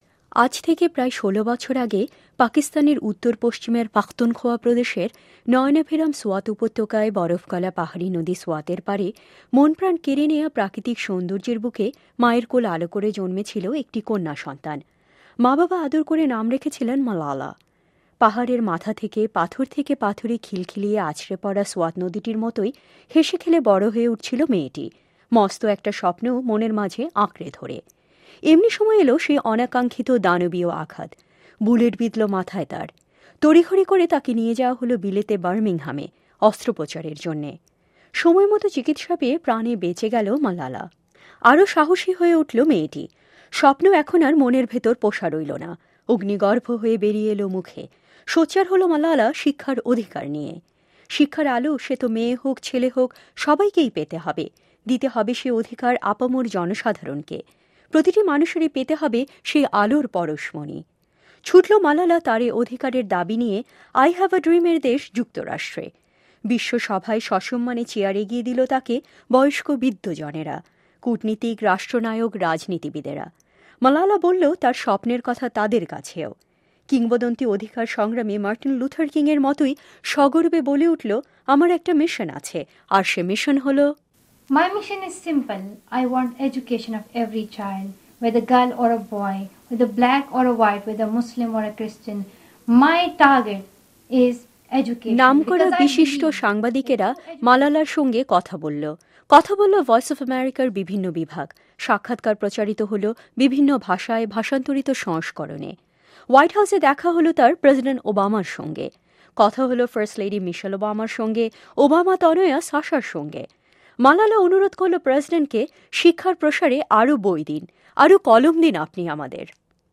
malala interview